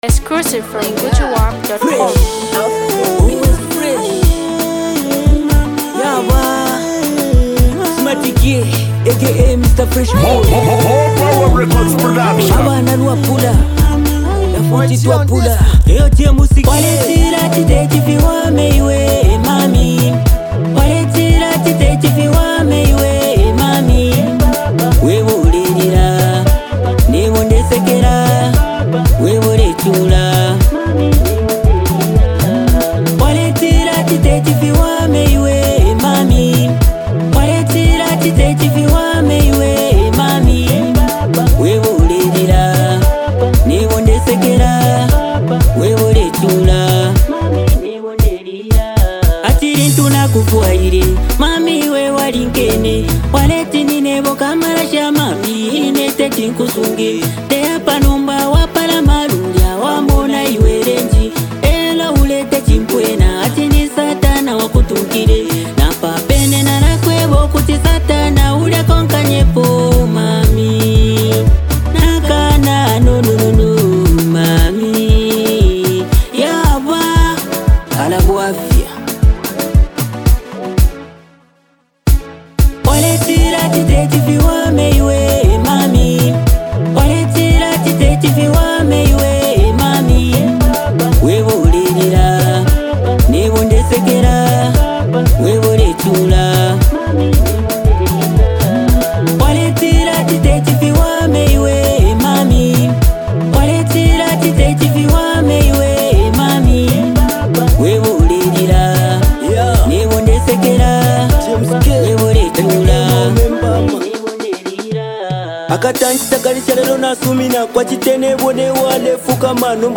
catchy track
Soulful Harmonies